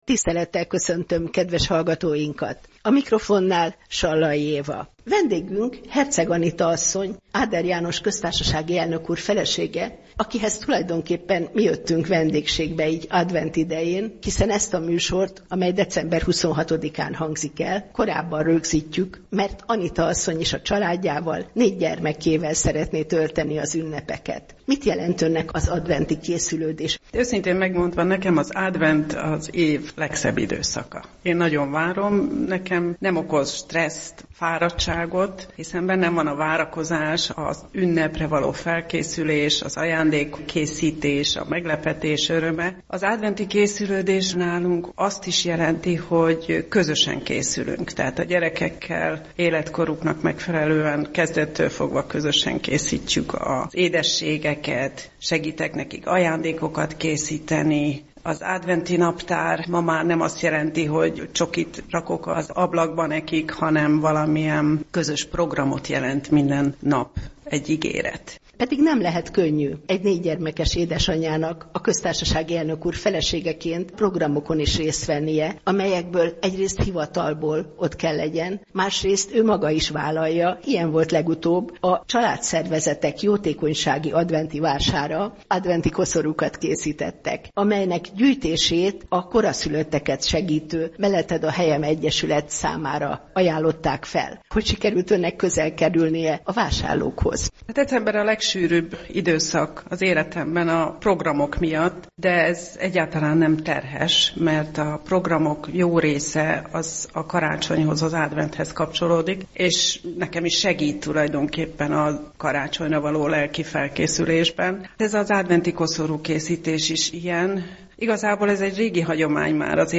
Karácsonyi interjú a Katolikus Rádióban